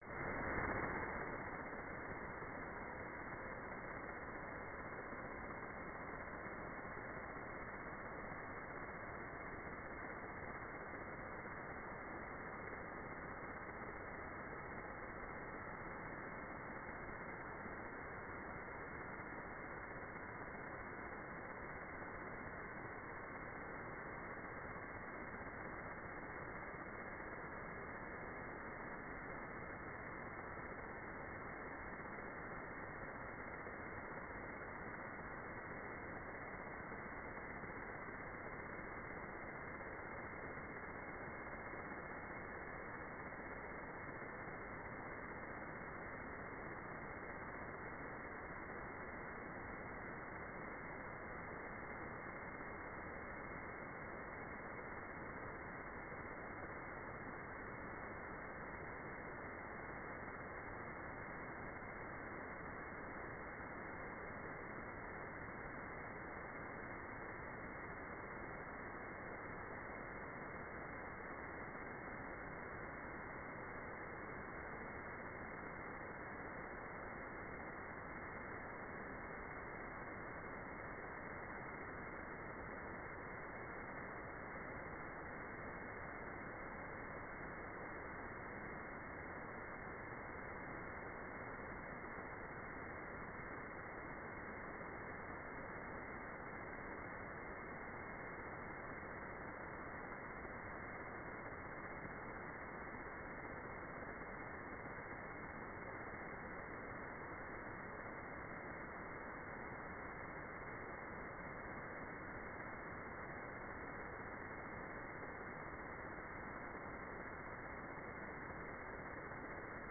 "waterfall_status": "without-signal",